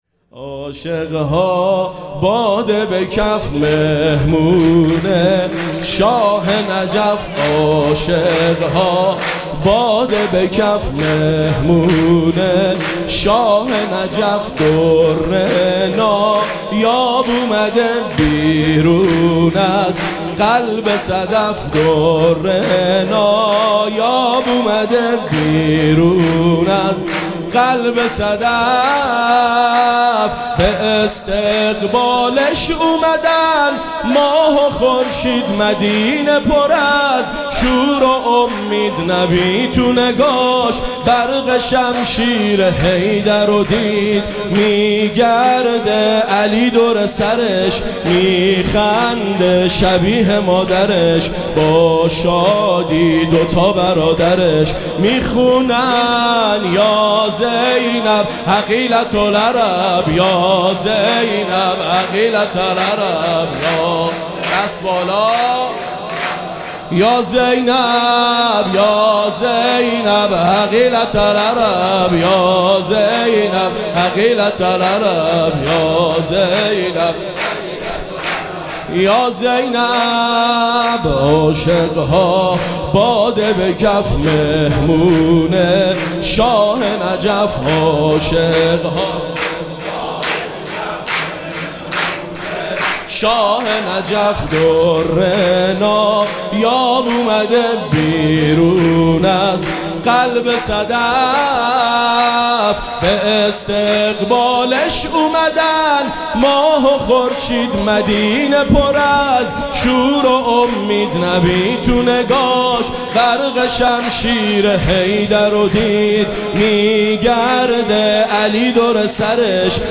مراسم جشن ولادت حضرت زینب سلام‌الله‌علیها
سرود
مداح